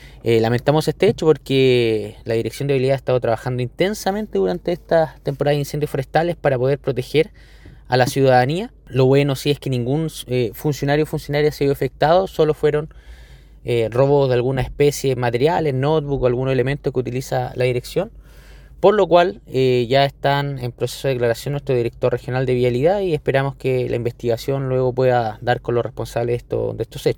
Por su parte, el seremi del MOP en La Araucanía, Patricio Poza, confirmó que entre los objetos robados hay notebooks utilizados por los funcionarios.
cu-patricio-poza.mp3